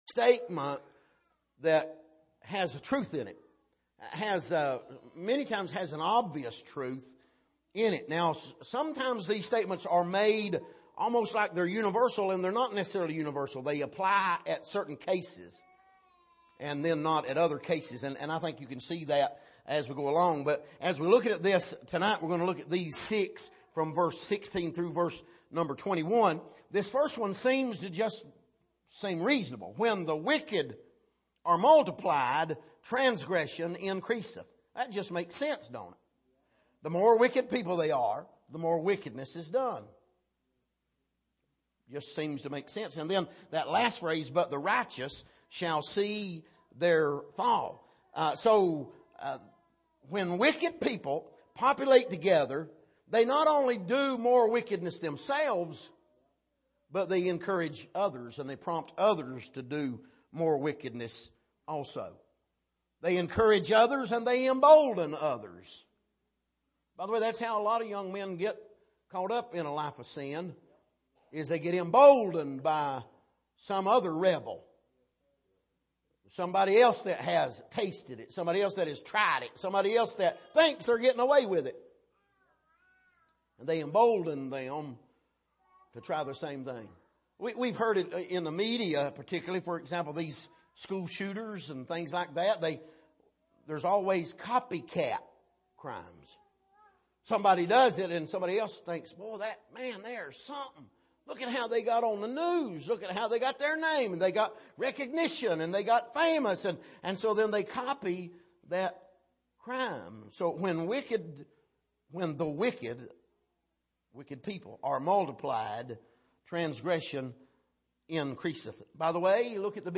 Passage: Proverbs 29:16-21 Service: Sunday Evening